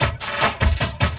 45LOOP SD1-L.wav